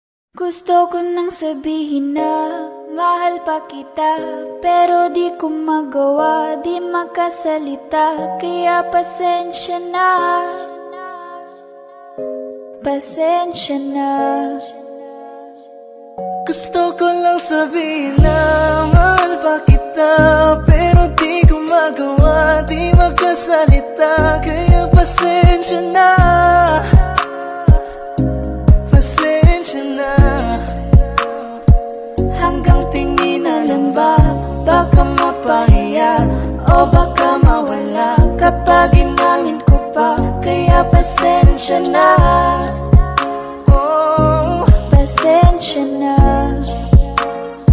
with their mashup